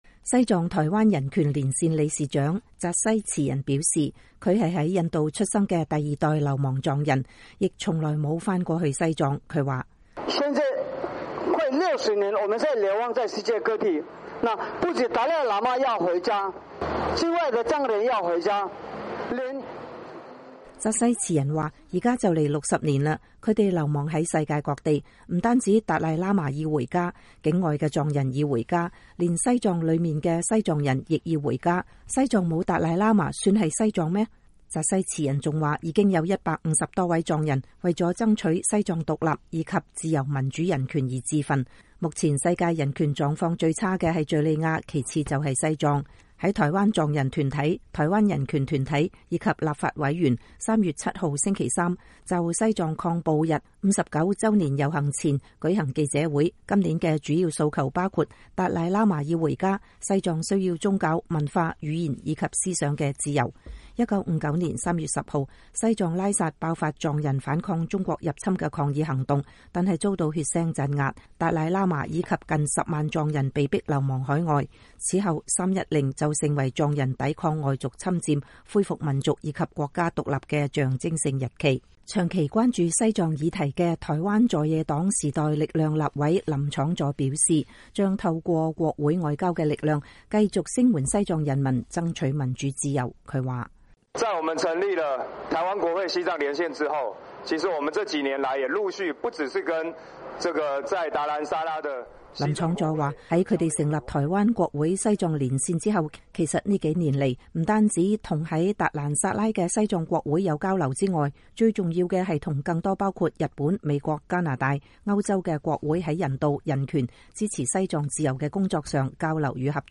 西藏抗暴日59週年遊行記者會